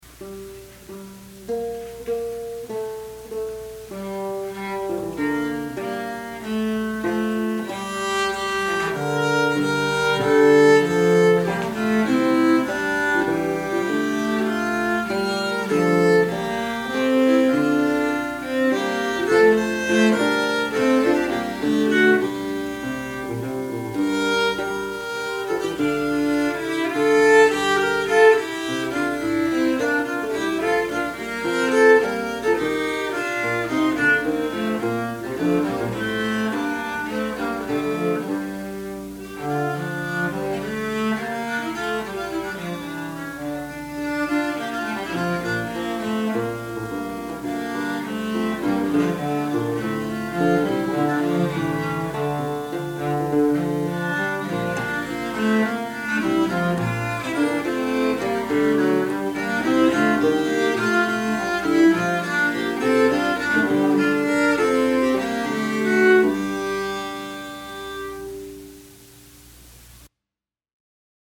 viol
lute